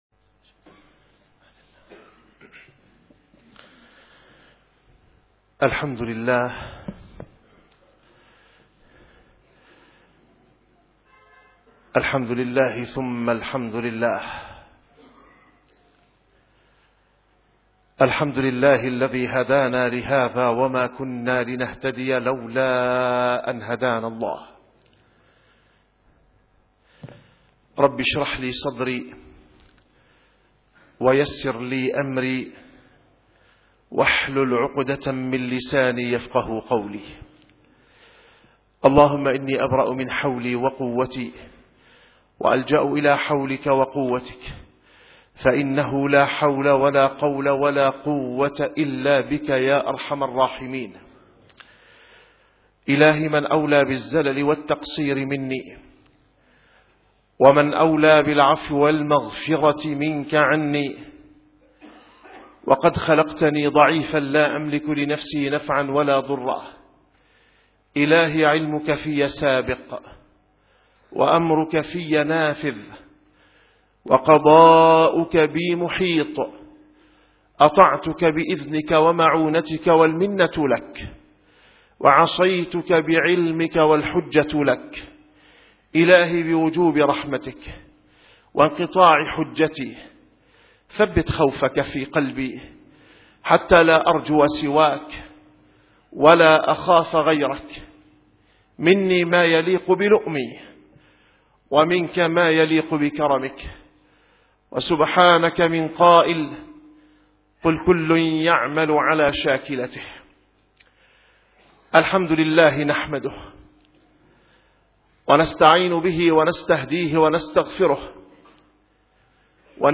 - الخطب - ويأبى الله إلا أن يتم نوره